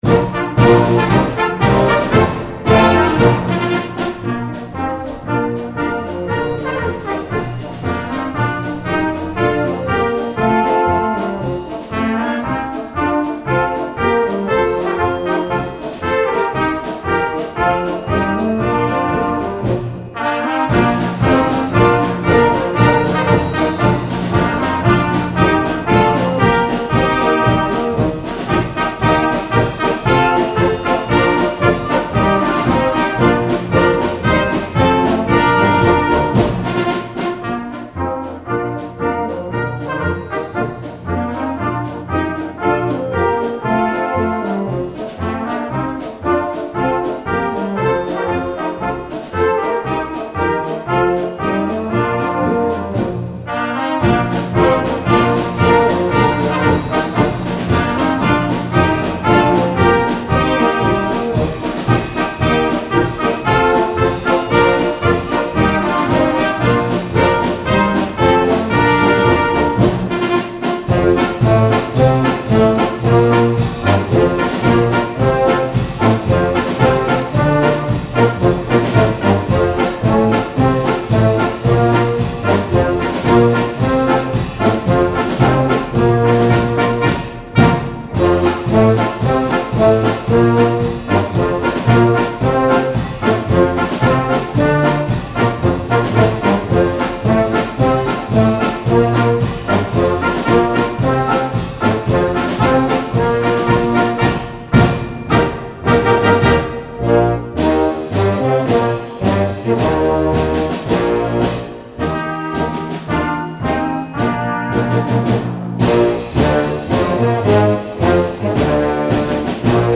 Gattung: Marsch für Jugendblasorchester
Besetzung: Blasorchester